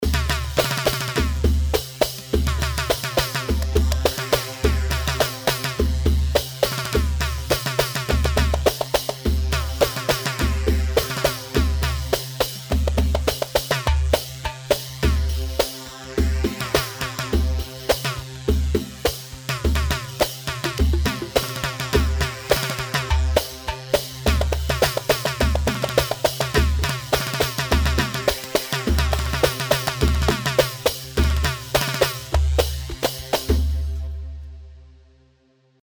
Hewa 4/4 104 هيوا